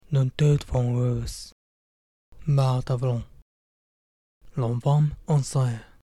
鼻母音
鼻母音 nの前で鼻母音化する。
ユンク語が元であるため、後ろ舌傾向が目立つ。
アクセント アクセントは常に最終音節に置かれる。